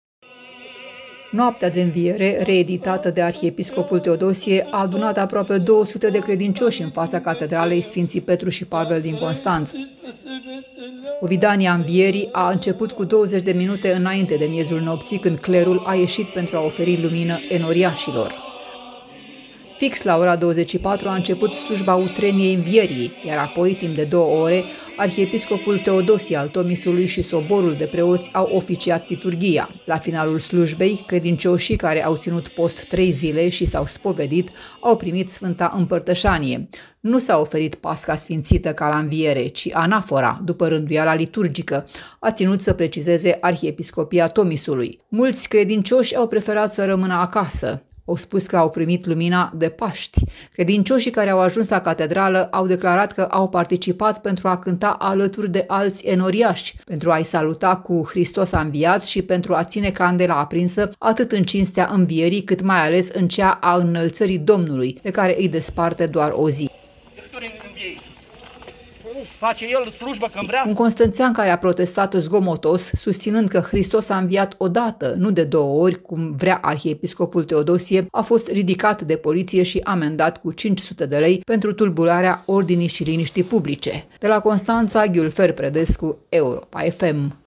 Aproape două sute de credincioşi au mers, marţi seară, la Catedrala din Constanţa, unde arhiepiscopul Teodosie i-a chemat să le ofere Lumina Învierii ca în noaptea de Paşti.